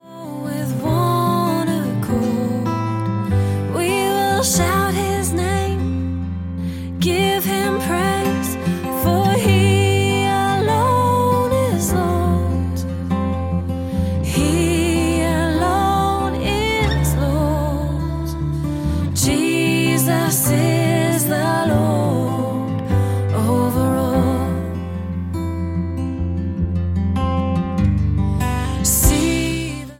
worship songs